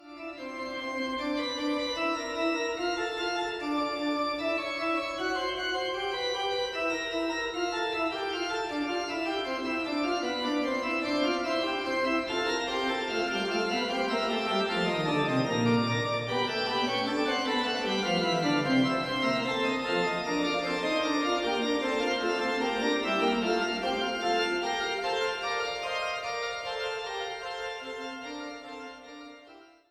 Eule-Orgel im Dom zu Zeitz
Orgel